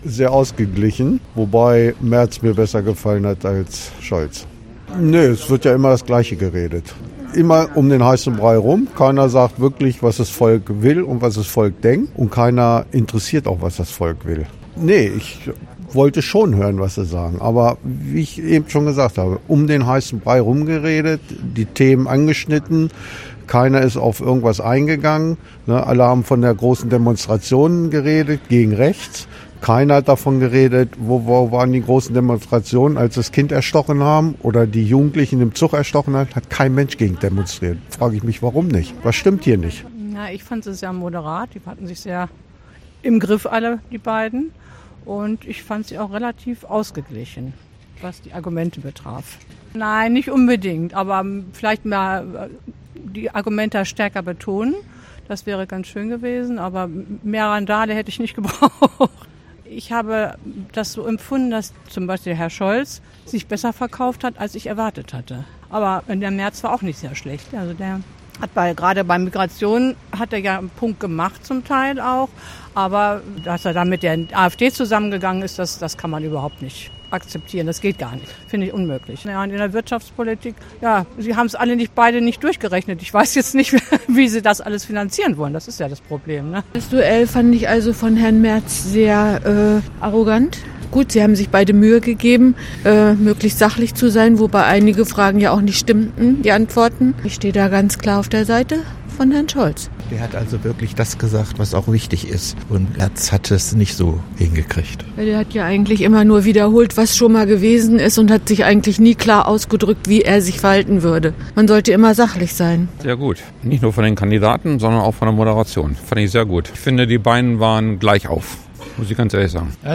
Umfrage: TV-Kanzlerduell